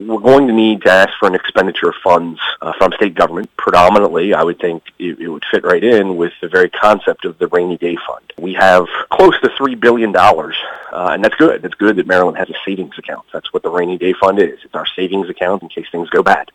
Unless there is a major change, Allegany County is going to be looking for every source available to cover what FEMA did not in denying disaster funding. Delegate Jason Buckel tells WCBC that area legislators will most likely be asking for access to monies in the state’s Rainy Day Fund to cover repair and restoration…